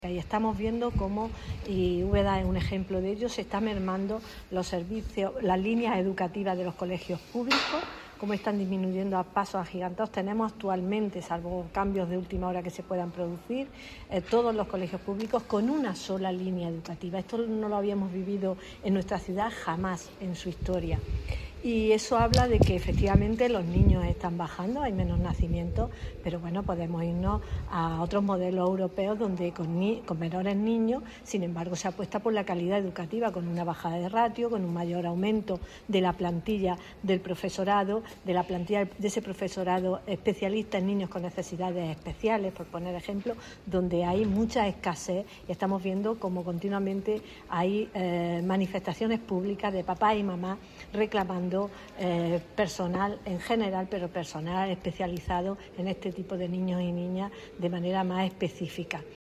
De la Rosa, que ha participado en un Foro sobre Educación organizado por el PSOE de Jaén en la ciudad de Úbeda, manifestó que la educación construye sociedad y que hay dos modelos: una educación “para unir” y otra “para segregar”.